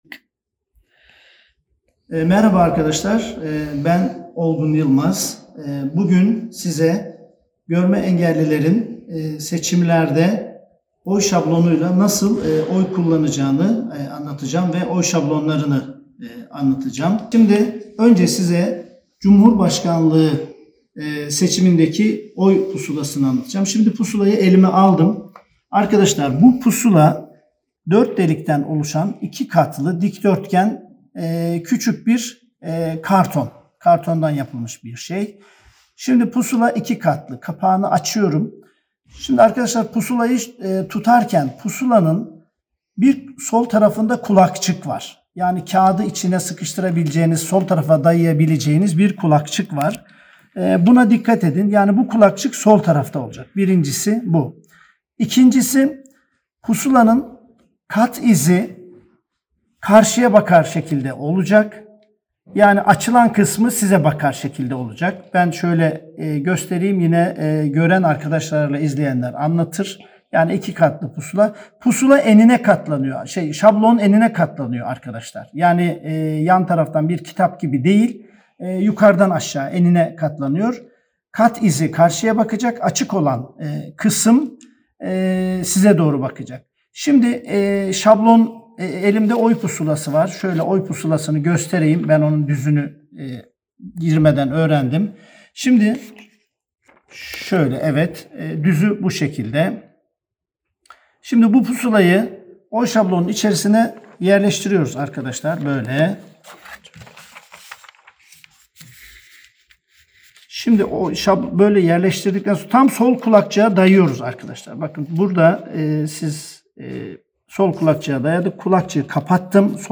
Bu nedenle, üretilen oy şablonlarının en erişilebilir şekilde nasıl kullanılabileceğine ilişkin Yüksek Seçim Kurulu binasında bir video çekerek sizlerle paylaşmak istedik.